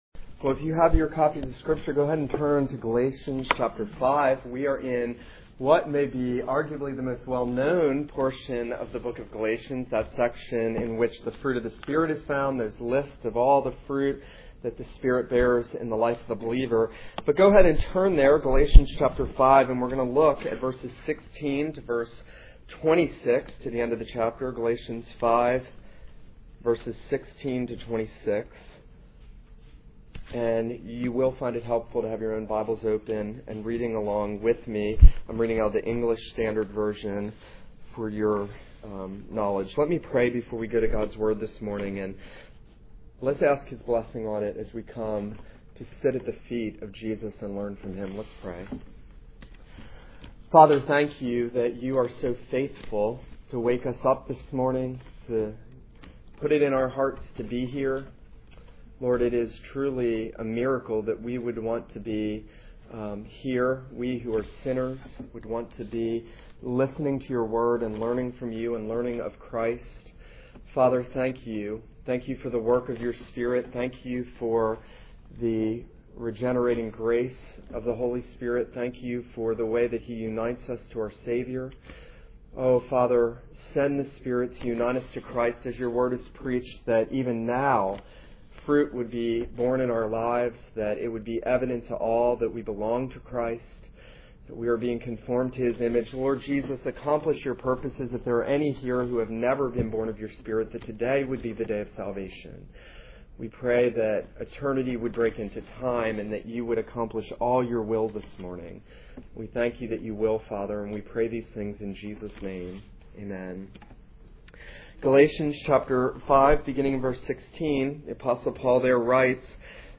This is a sermon on Galatians 5:16-21.